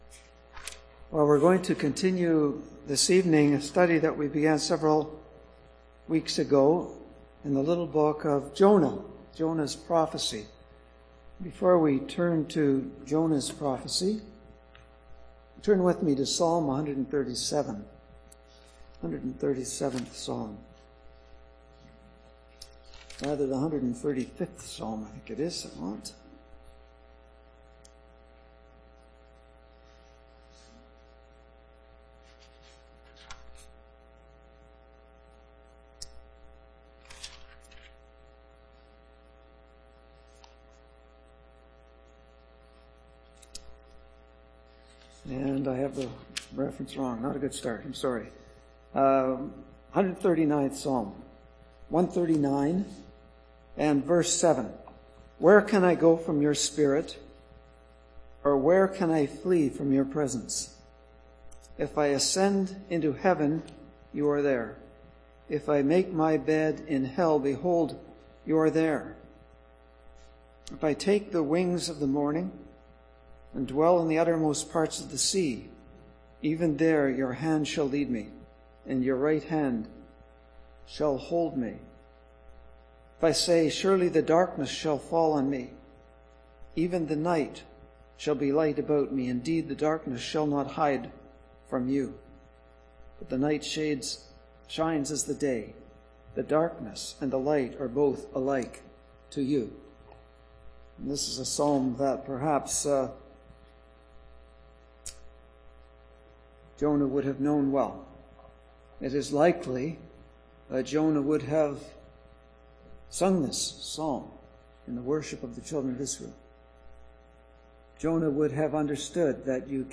Passage: Psalm 139:7-12, Jonah 4 Service Type: Sunday PM